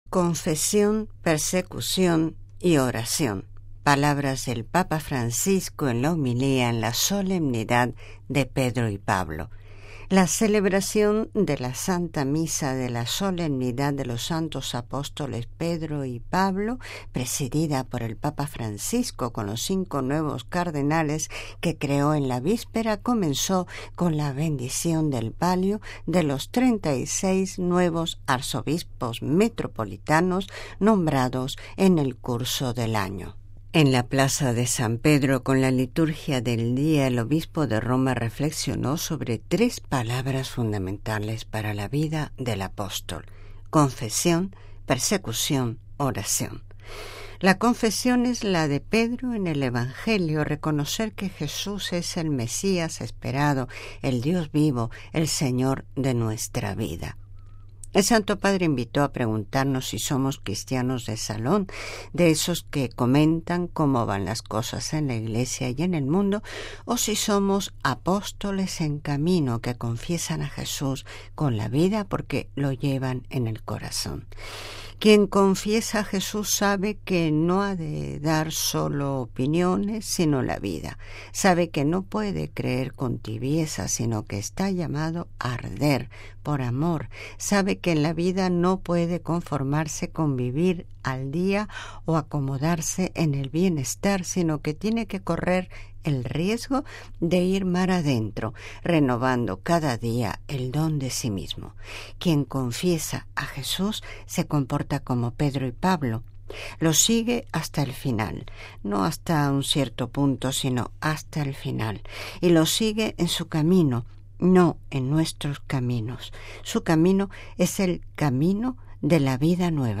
Papa: confesión, persecución y oración. Homilía en la Solemnidad de Pedro y Pablo
(RV).- La celebración de la Santa Misa de la Solemnidad de los Santos Apóstoles Pedro y Pablo, presidida por el Papa Francisco con los cinco nuevos Cardenales, que creó en la víspera, comenzó con la bendición del Palio de los 36 nuevos Arzobispos Metropolitanos, nombrados en el curso del año.
En la Plaza de San Pedro, con la liturgia del día, el Obispo de Roma reflexionó sobre tres palabras fundamentales para la vida del apóstol: confesión, persecución, oración.